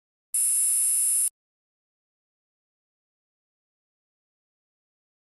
Buzz, Electronic High Pitch